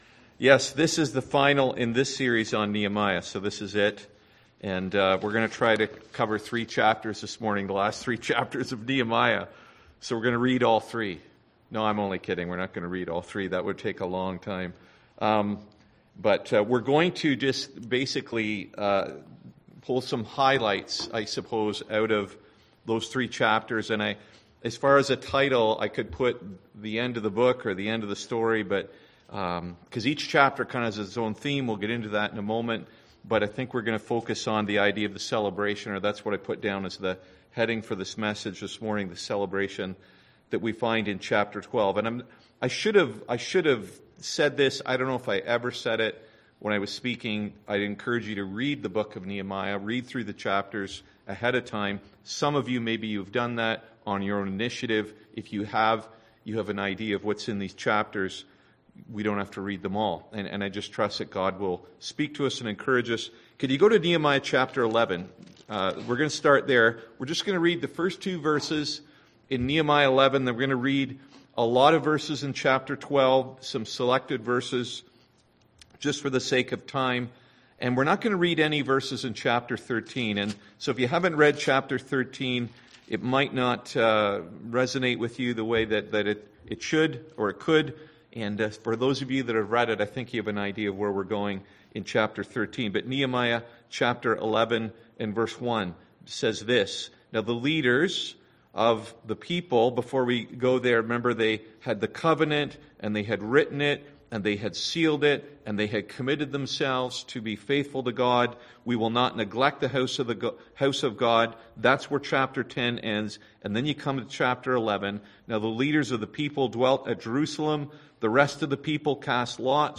Series: Nehemiah 2021 Passage: Nehemiah 11-13 Service Type: Sunday AM